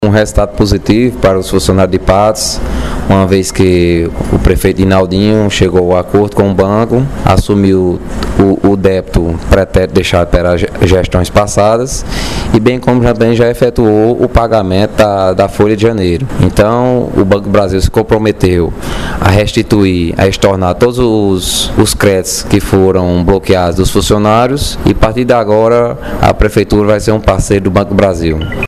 Fala do procurador geral do município, Phillipe Palmeira –